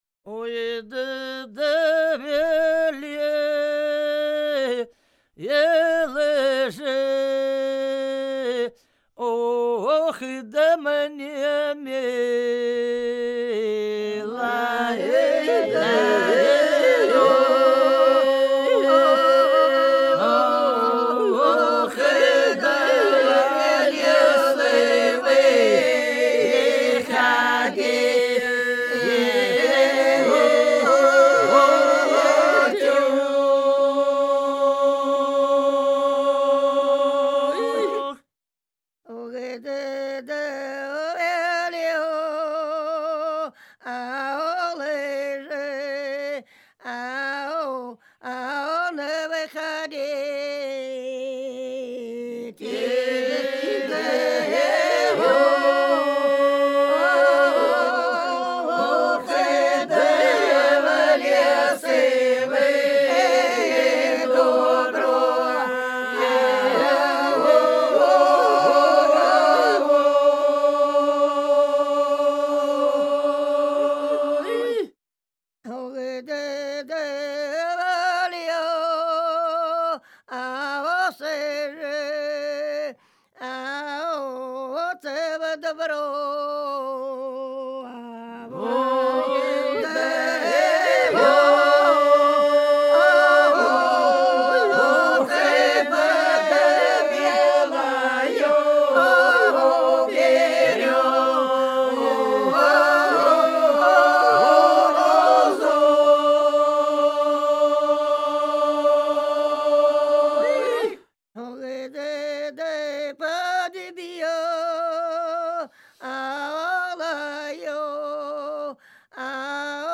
Ансамбль села Хмелевого Белгородской области Ой, да велел мне милый в лес выходити (стяжная, поется под медленный шаг; с Пасхи до Вознесения)